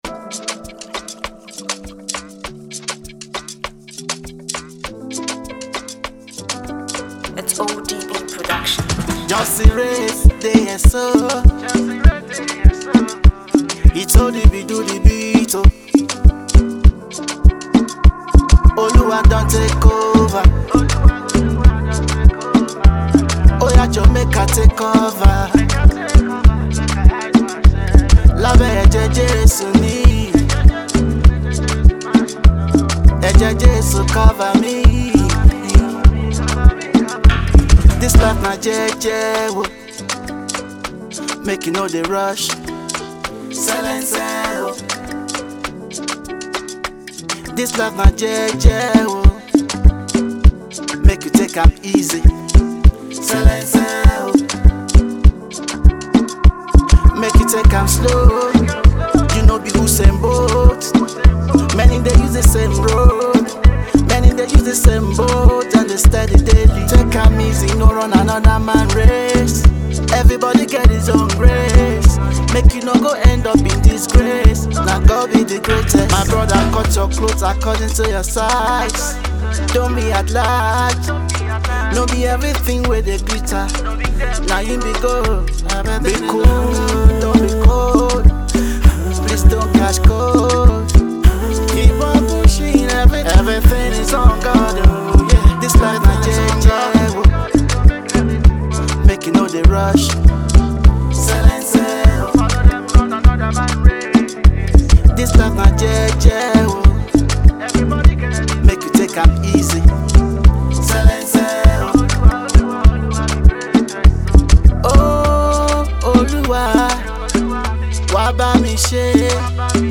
dynamic gospel music minister and prolific songwriter